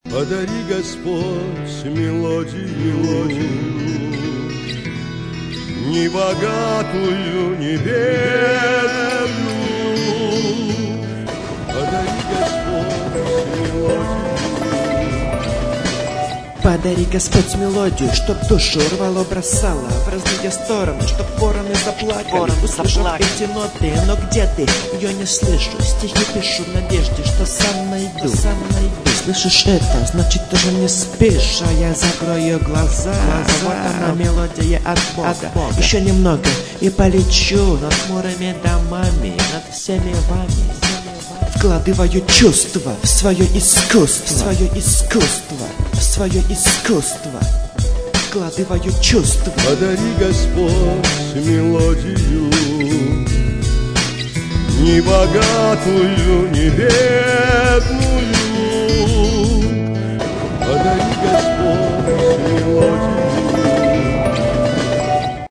20 05 Рэп